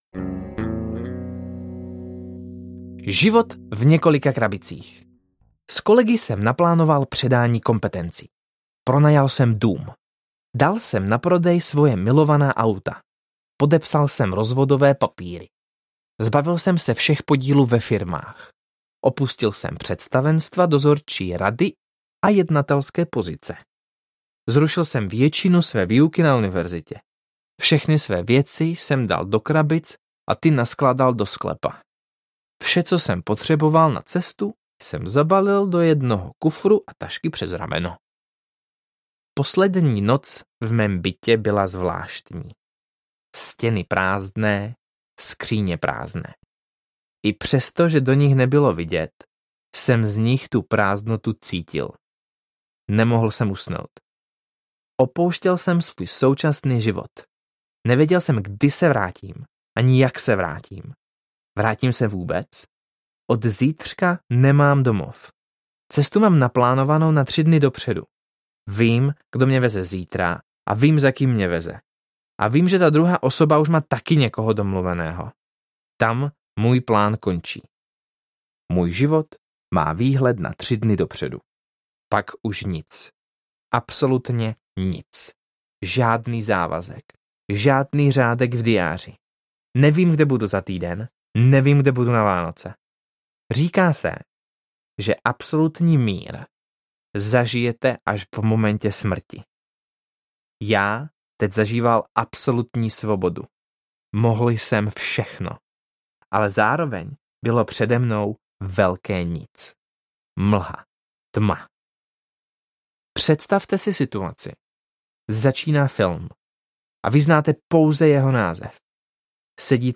Jak jsem stopoval letadlo audiokniha
Ukázka z knihy